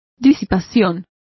Complete with pronunciation of the translation of dissipation.